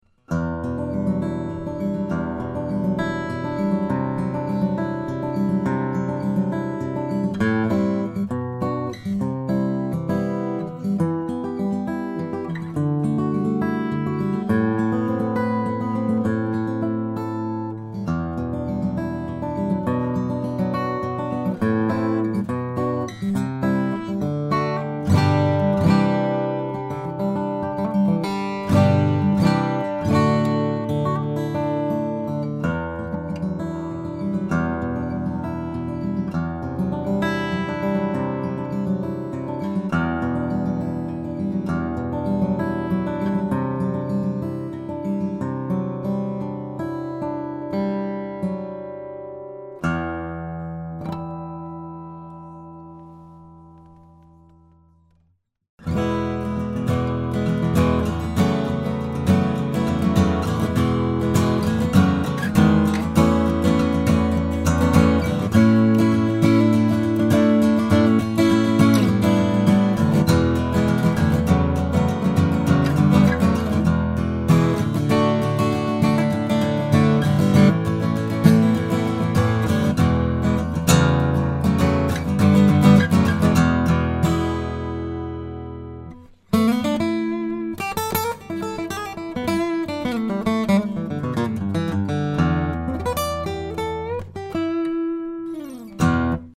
A Jumbo body of Indian Rosewood and Sitka Spruce that produces a big, clear Lowden voice. The trebles are bright and strong and bass is resounding.